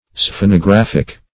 Sphenographic \Sphe`no*graph"ic\, a. Of or pertaining to sphenography.